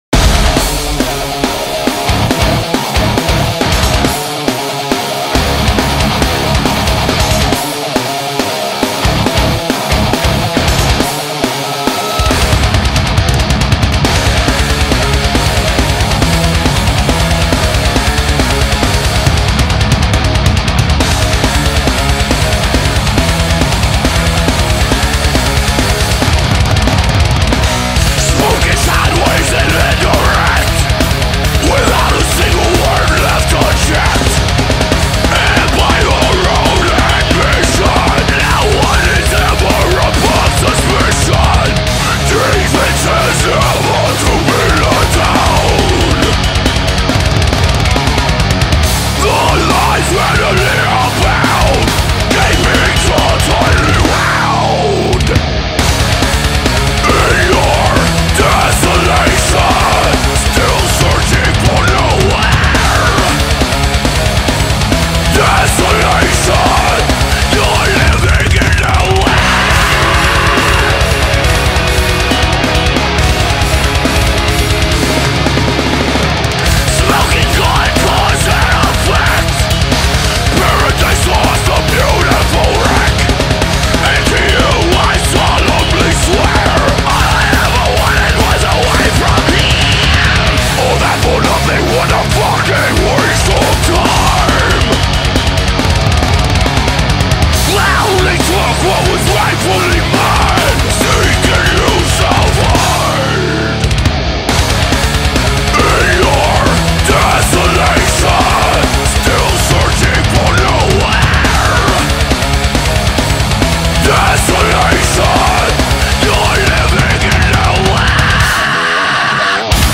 They're NWOAHM so a mix of groove, core, southern…